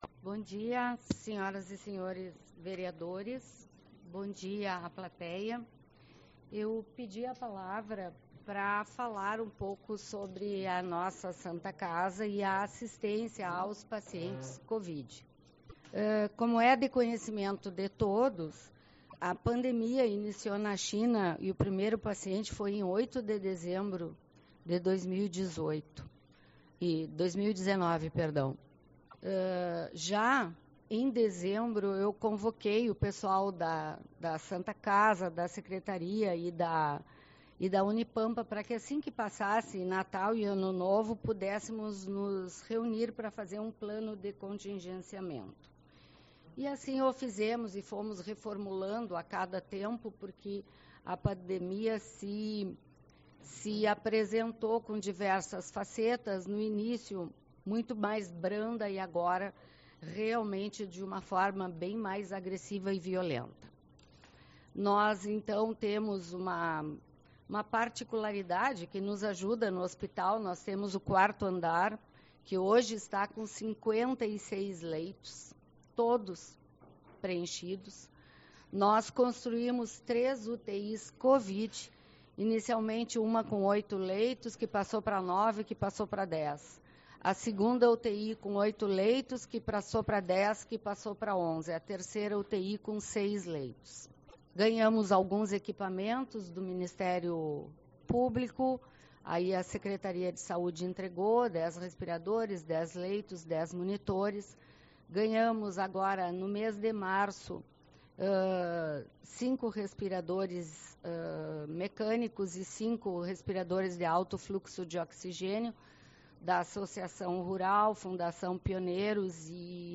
06/04 - Reunião Ordinária